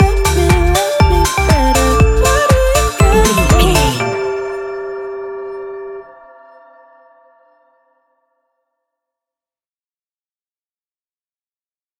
Ionian/Major
house
electro dance
synths
techno
trance